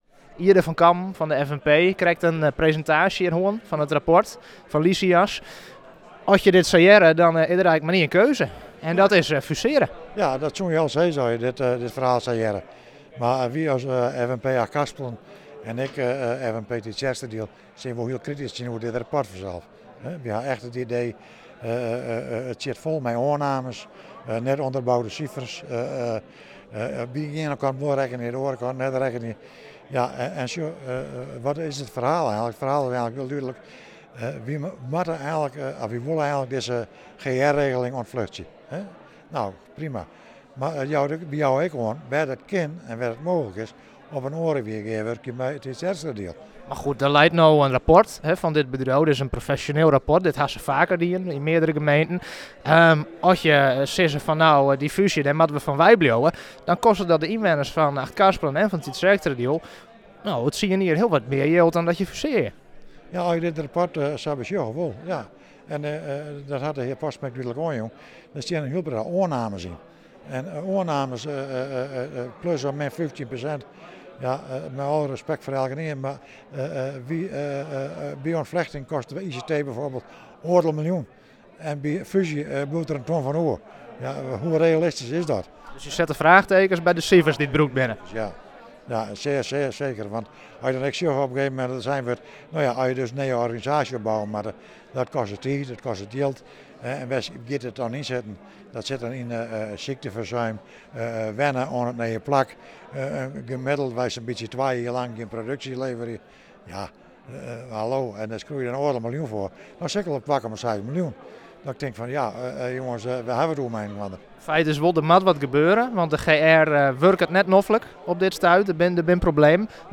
Raadslid IJde van Kammen van de FNP in Achtkarspelen: